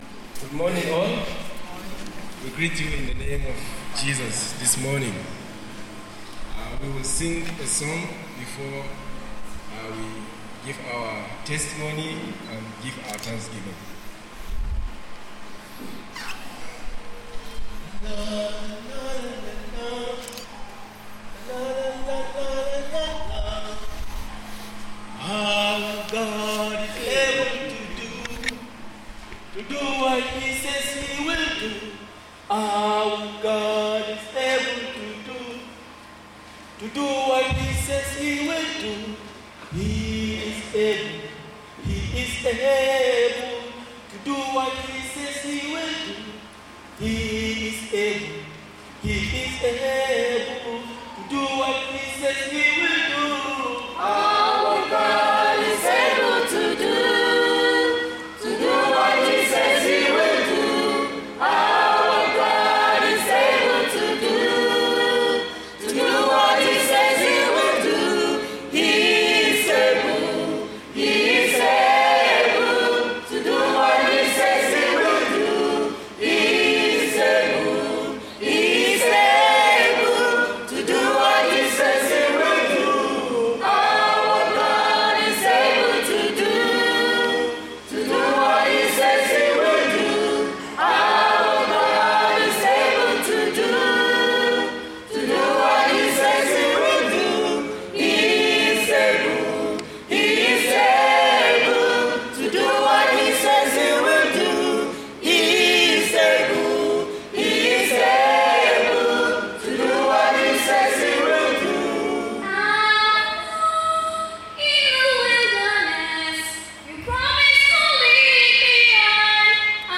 Here are five songs performed by the staff members of the ECWA Information and Computer Sciences Institute.
EICSI staff rehearsed all week to learn this song.  They performed it at ECWA headquarters during Sunday worship.